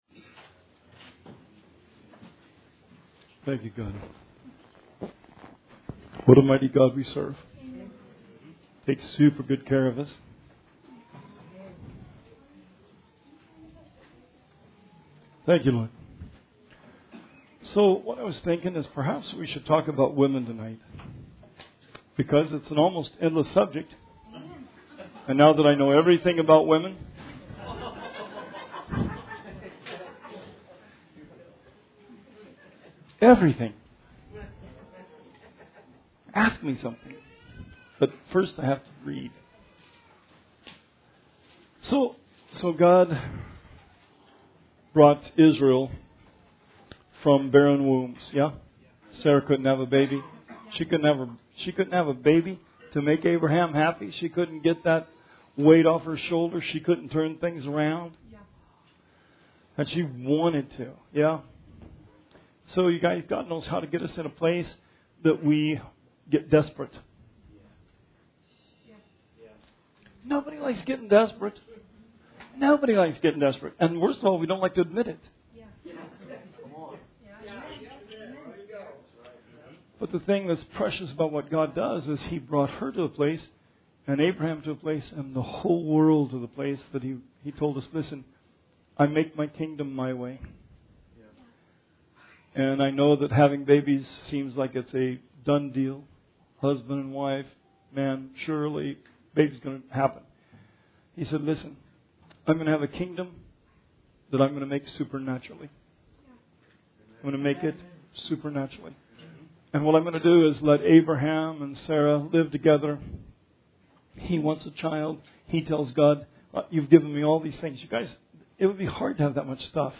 Bible Study 9/11/19